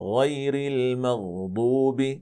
a ـــ Blanda dess ljud med [qāf — ق], som i:
غَيۡرِ ٱلۡمَغۡضُوبِ﴿           Det ska uttalas på följande sätt: